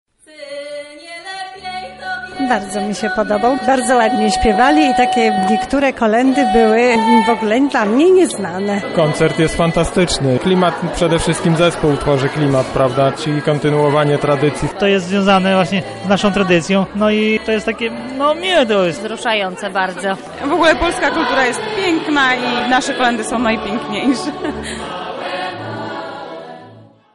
O swoich wrażeniach po koncercie mówią widzowie: